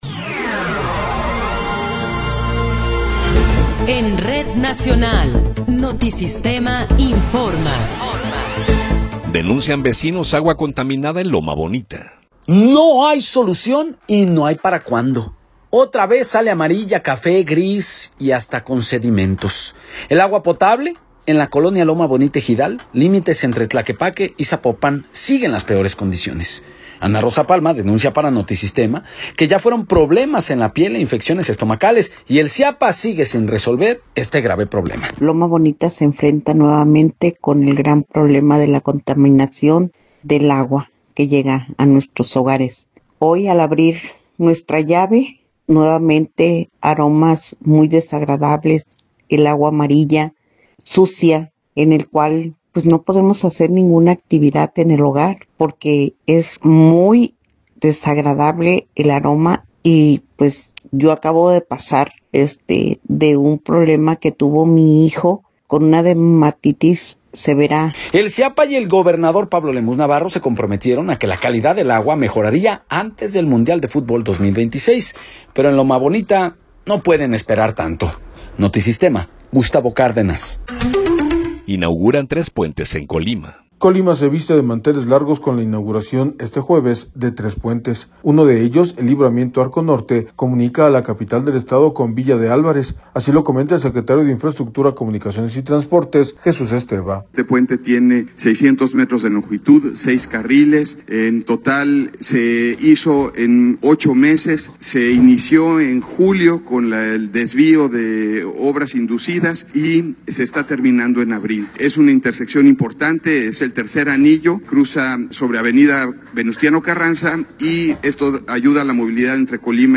Noticiero 19 hrs. – 23 de Abril de 2026